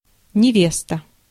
Ääntäminen
IPA: /nʲɪˈvʲestə/